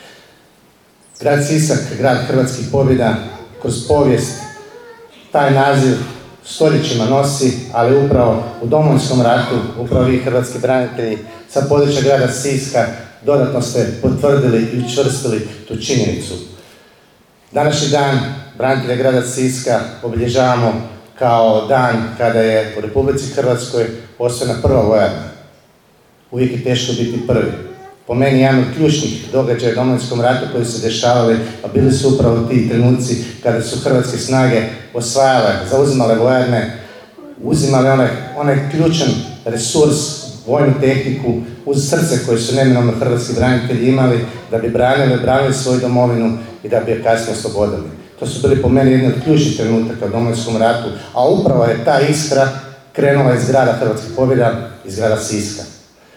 Hrvatskim braniteljima grada Siska njihov dan čestitao je i zamjenik župana Sisačko-moslavačke županije Mihael Jurić